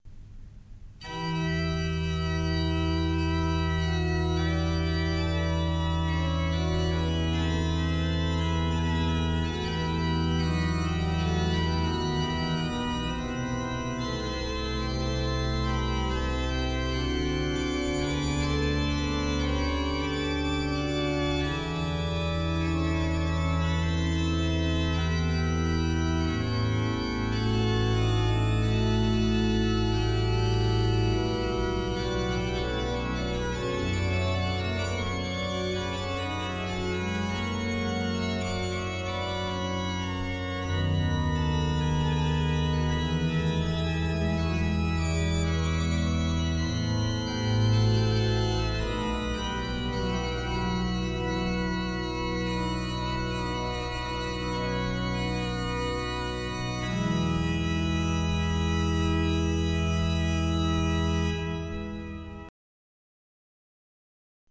Frescobaldi, toccata (dai Fiori musicali).mp3 — Laurea Magistrale in Culture e Tradizioni del Medioevo e del Rinascimento